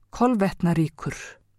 framburður